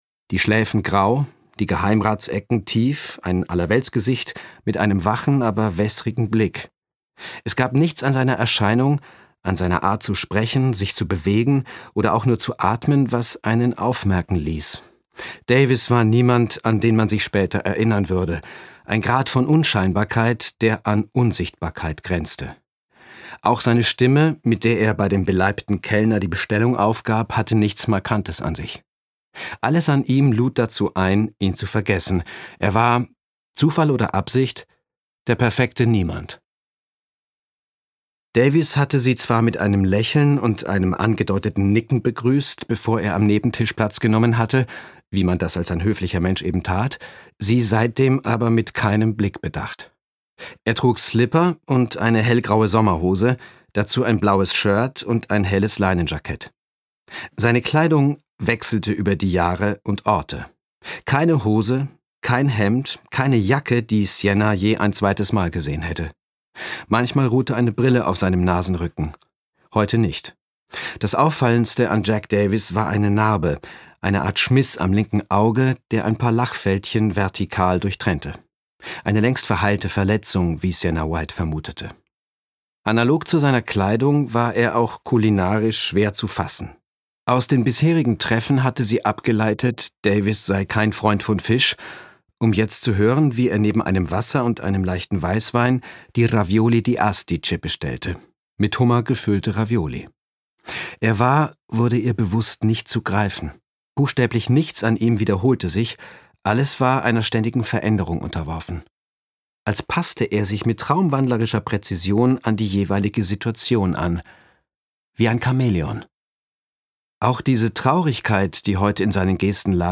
Andreas Pietschmann (Sprecher)
Gekürzte Lesung mit Andreas Pietschmann